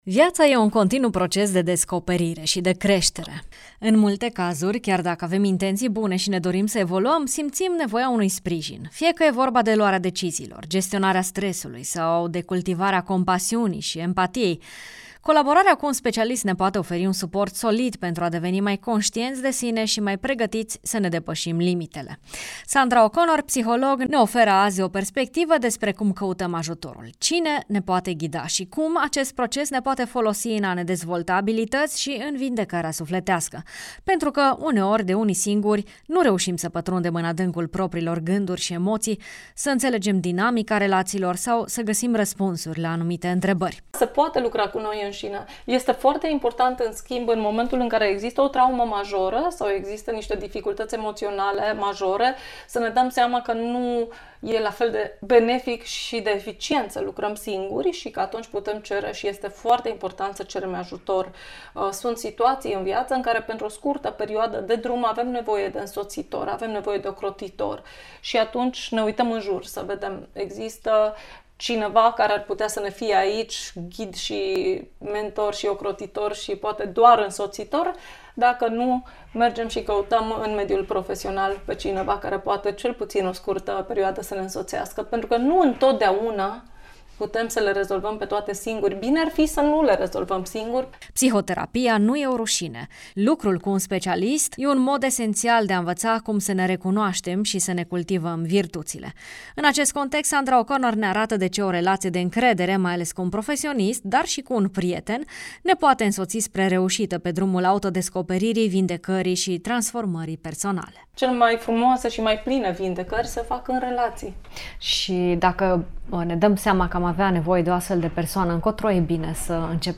A merge la psiholog nu este o rușine, ci o modalitate frumoasă de a te învăța să îți înțelegi mai bine mintea și emoțiile.